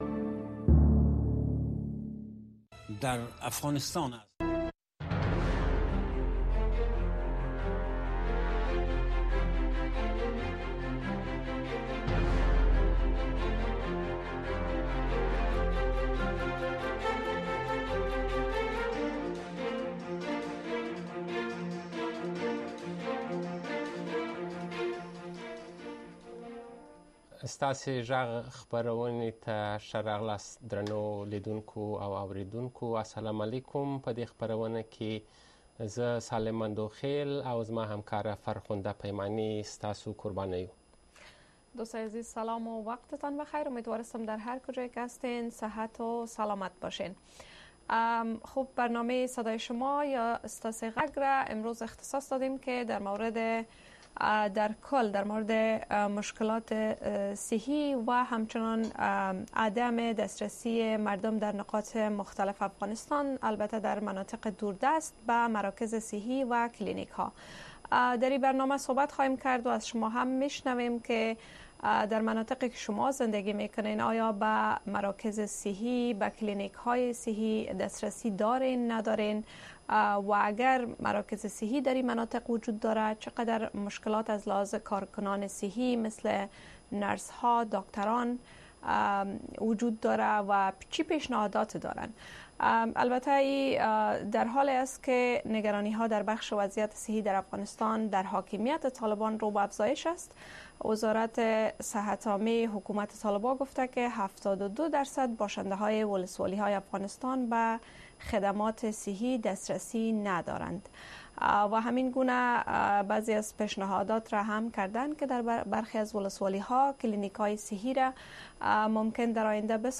در برنامۀ صدای شما، شنوندگان رادیو آشنا صدای امریکا مستقیماً با ما به تماس شده و نگرانی‌ها، دیدگاه‌ها، انتقادات و شکایات شان را با گردانندگان و شنوندگان این برنامه در میان می‌گذارند. این برنامه به گونۀ زنده از ساعت ۹:۳۰ تا ۱۰:۳۰ شب به وقت افغانستان نشر می‌شود.